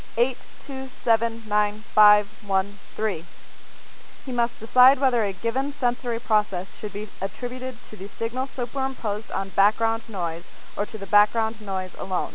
Again, you will hear a list of 7 numbers. Then, you will hear a sentence.